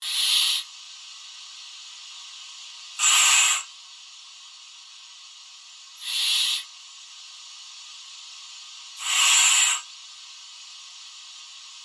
Pressure Suit Ventilator
UI/UX
Pressure Suit Ventilator is a free ui/ux sound effect available for download in MP3 format.
yt_ibu9gQTS7Jo_pressure_suit_ventilator.mp3